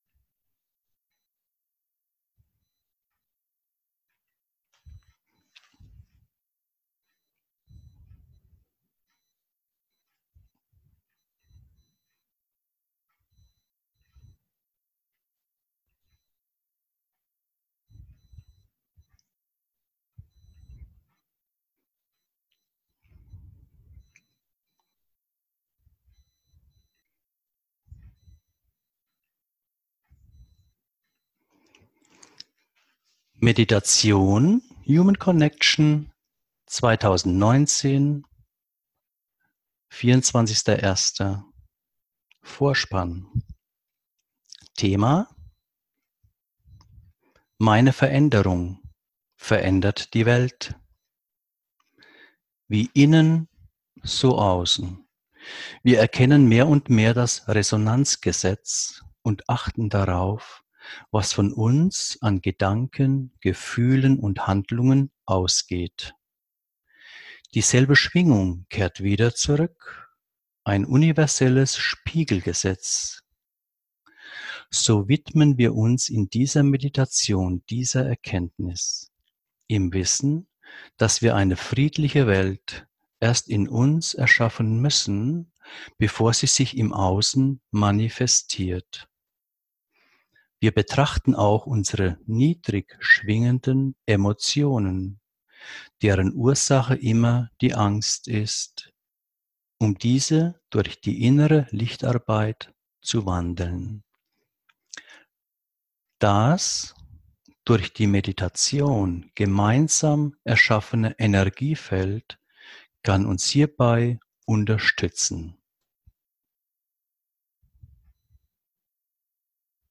Um die Veränderung der Welt durch eigenen Verängerung geht es in dieser geführten Meditation.